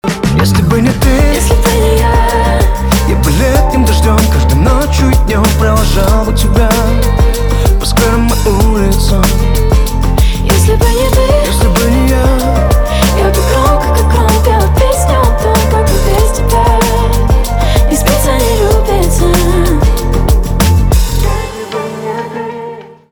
поп
романтические , битовые , гитара , чувственные